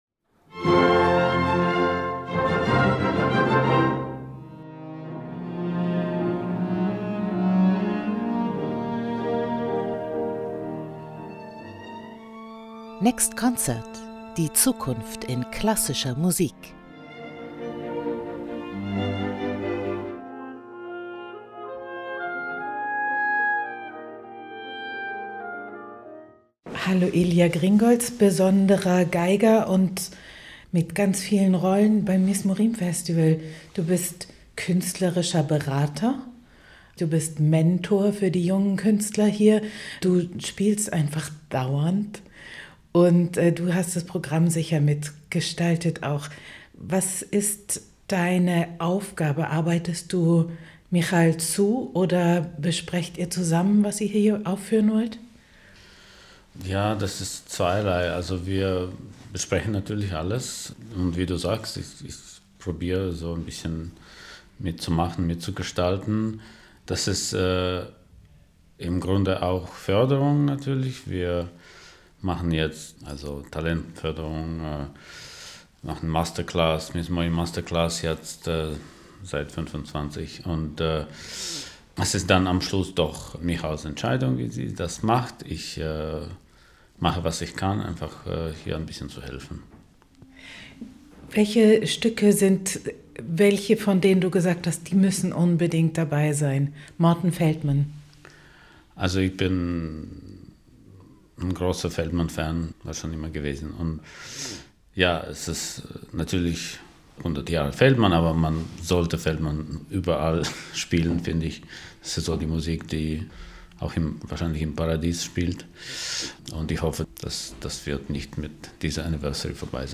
Mit dem Geiger spreche ich über seine Rolle als künstlerischer Berater beim Mizmorim Kammermusikfestival.